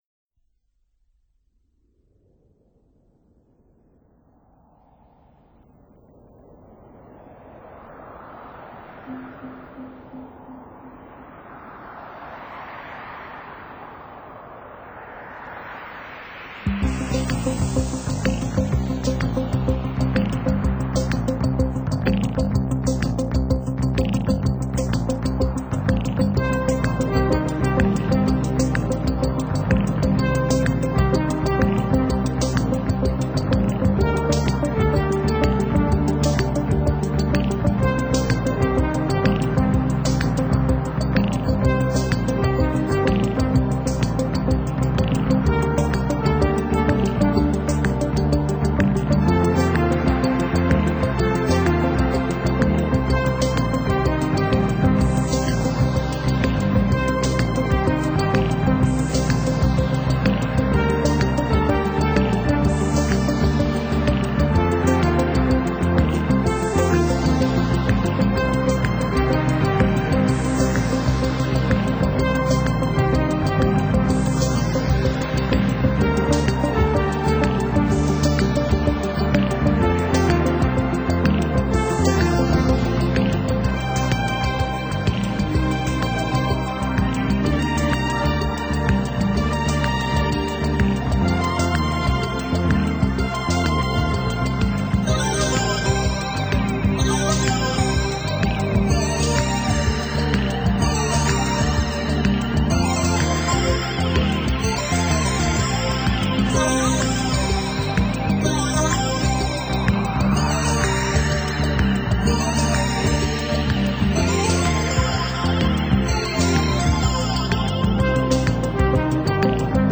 在戴上耳机的一瞬间你就 陷入了完全的音乐氛围中，真正的360度环绕声！